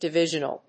音節di・ví・sion・al 発音記号・読み方
/‐ʒ(ə)nəl(米国英語)/